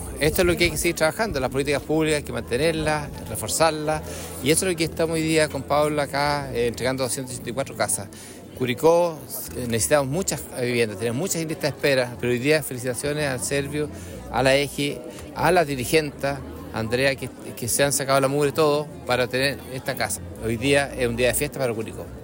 SERVIU_Parque-Bellavista-4_alcalde-Curico.mp3